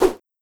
SFX_falloEspada2.wav